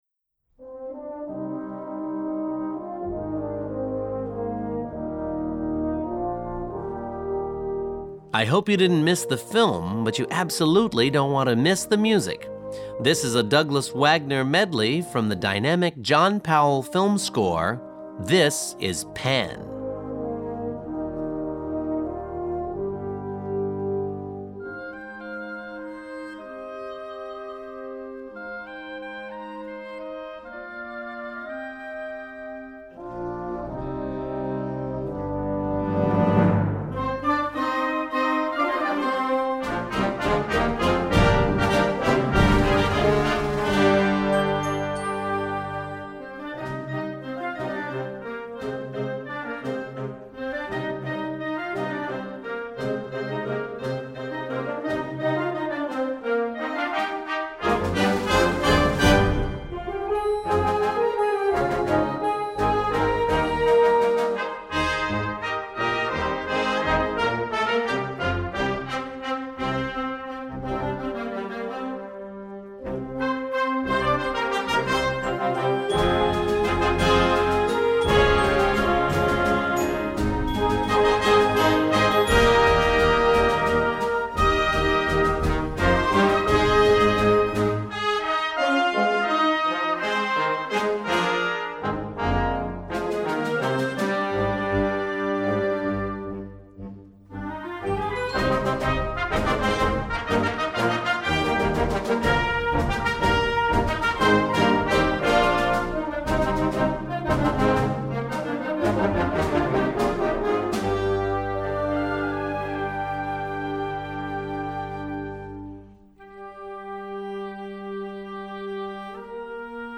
Gattung: Filmmusik-Medley
Besetzung: Blasorchester
Powerful!